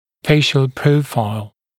[‘feɪʃl ‘prəufaɪl][‘фэйшл ‘проуфайл]профиль лица